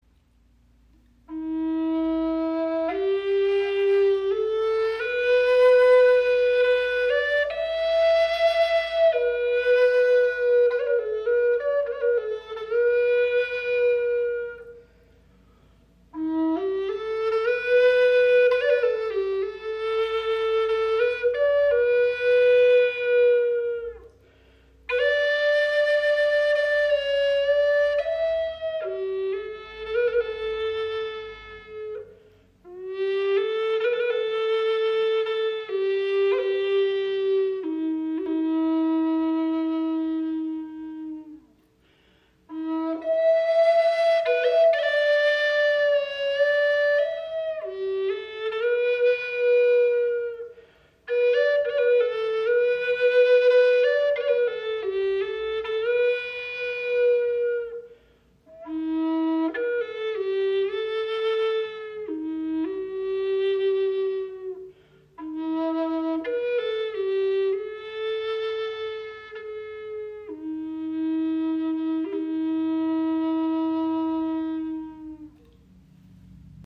Unsere 432 Hz Chakra Flöte in E-Moll trägt die tiefe, klare Stimme des Walnussholzes.